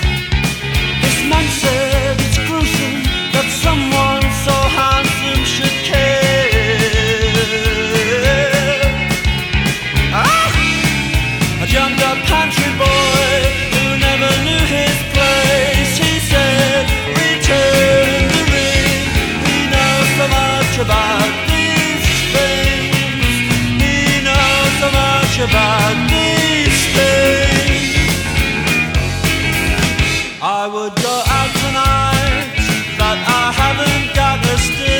Alternative College Rock Rock Adult Alternative Pop Pop Rock
Жанр: Поп музыка / Рок / Альтернатива